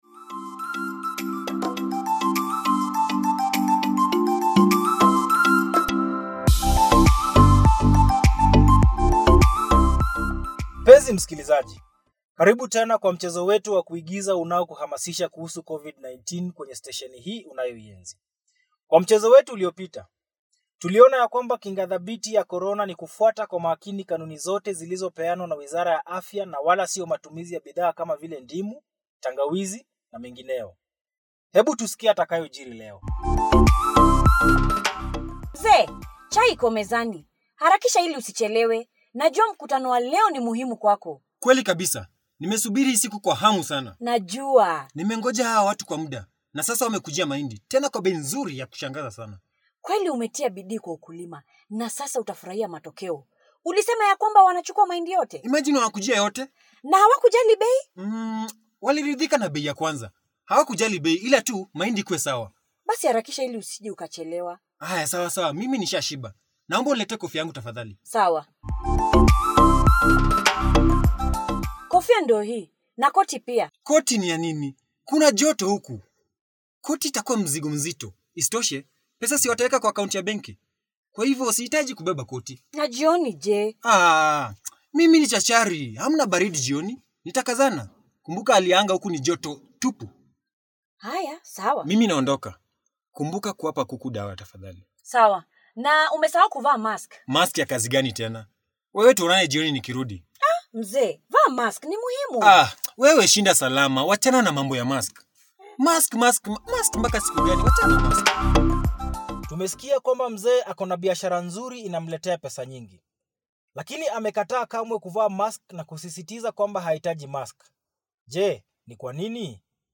Episode-2.-Radio-Drama.mp3